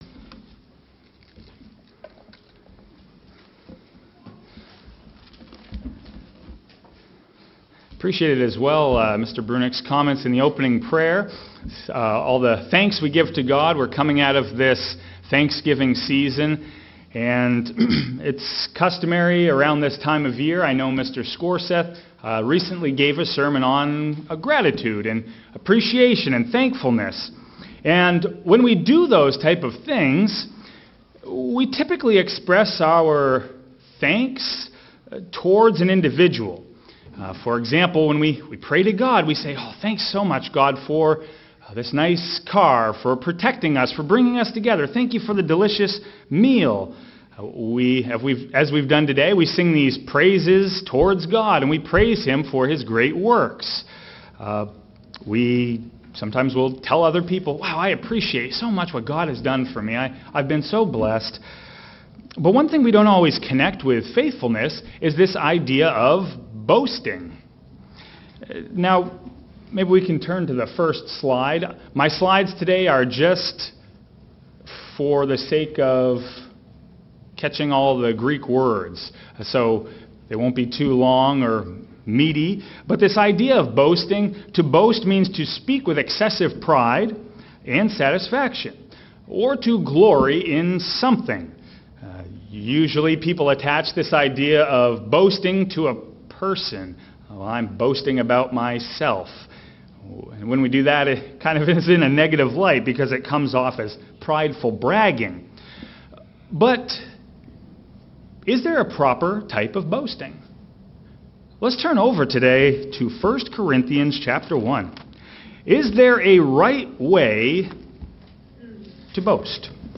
This sermon emphasizes the importance of boasting not in ourselves or others, but in God, as taught in 1 Corinthians 3. Paul contrasts a carnal mindset with a spiritual one, urging believers to rely on God’s strength and wisdom, rather than worldly pride, to build a strong foundation in Christ. Trials and challenges refine our faith like fire purifies metal, shaping us to reflect God's character.